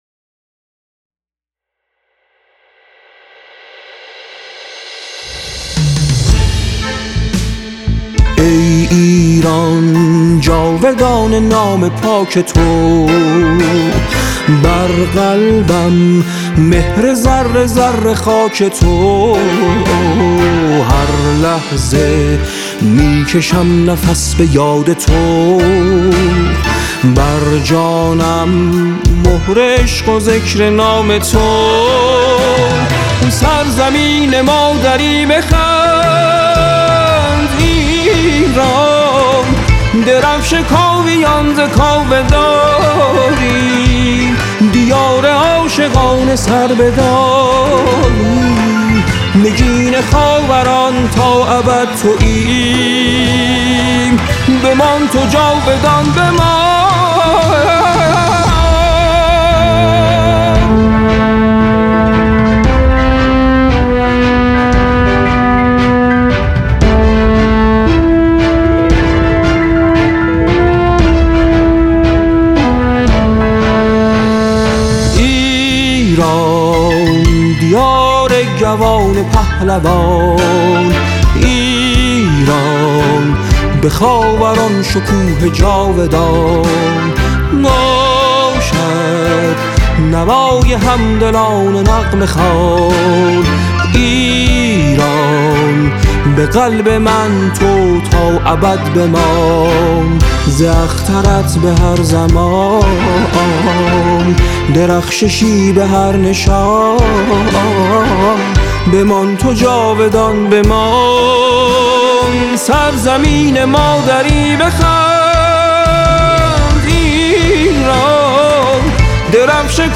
این اثر در گونه موسیقی تلفیقی با درون مایه حماسی و مطلع